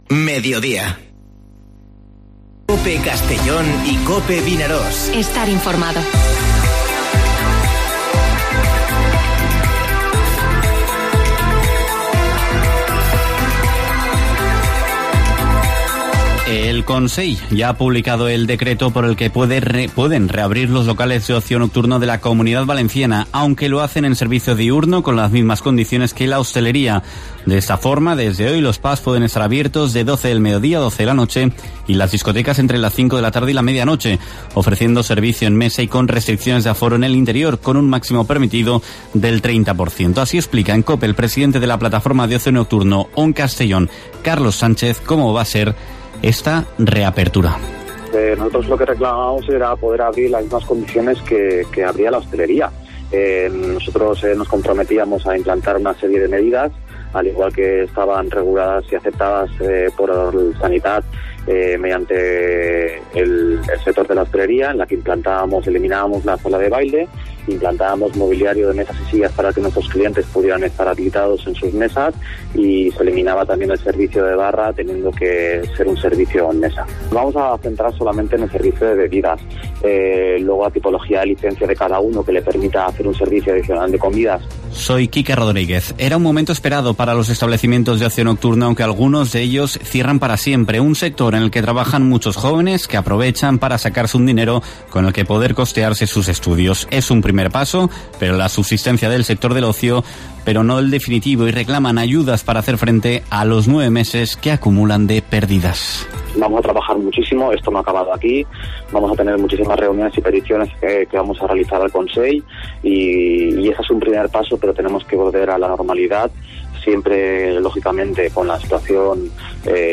Informativo Mediodía COPE en la provincia de Castellón (07/12/2020)